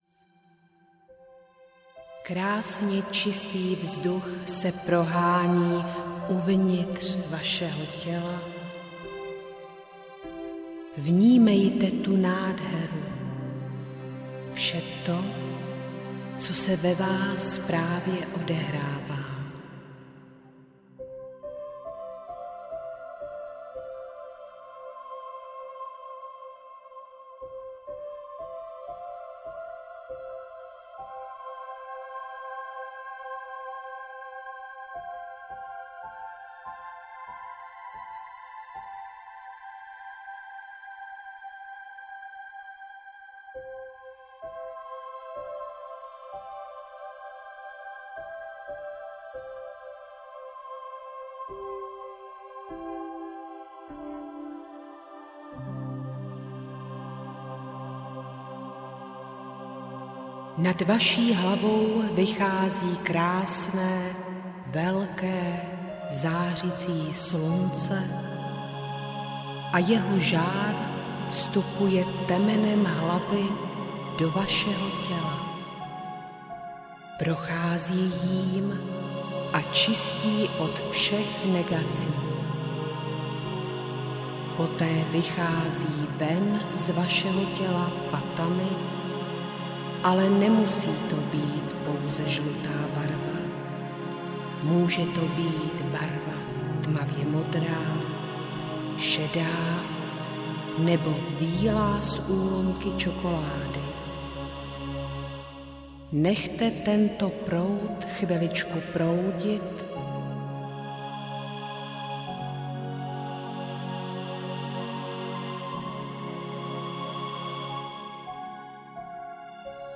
Meditace 1 - audiokniha obsahuje meditace zaměřené na uvolnění vnitřního napětí. Zaposlouchejte se do slov doprovázených hudbou, která vás povedou do vlastního nitra, kde budete moci následně pracovat sami na sobě.
Audio knihaMeditace 1
Ukázka z knihy